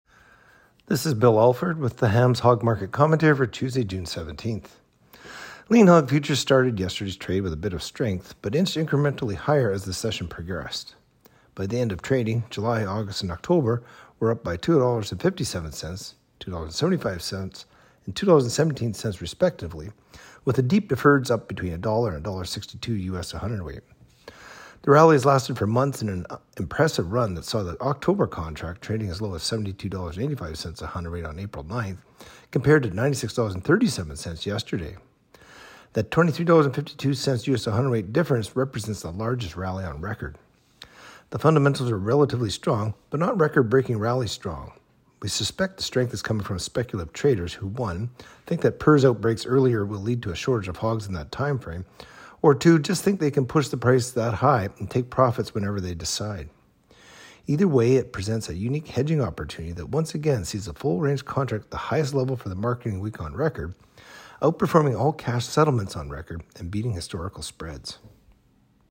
Hog-Market-Commentary-Jun.-17-25.mp3